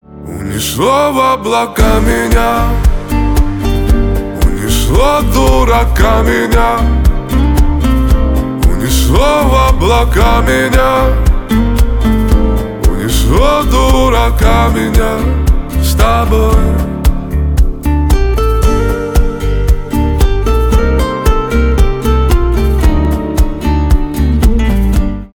красивый мужской голос , поп
романтические